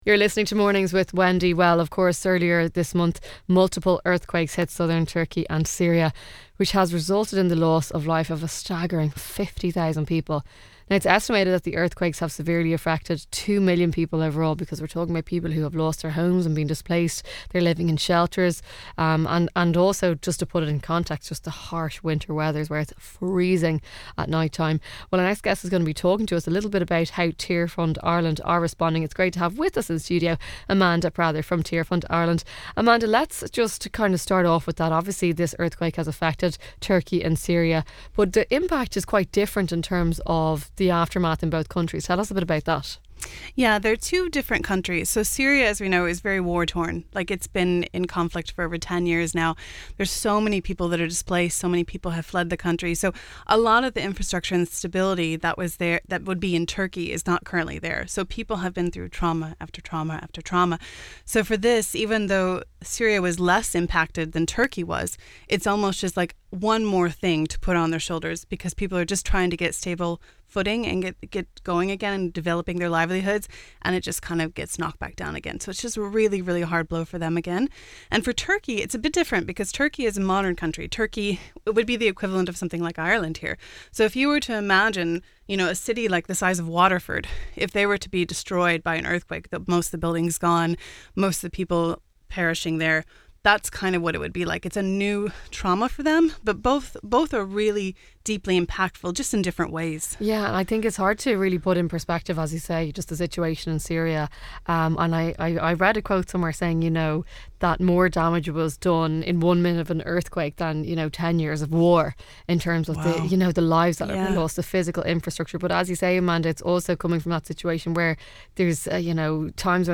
Tearfund Ireland is delighted to partner with national and local radio stations throughout Ireland.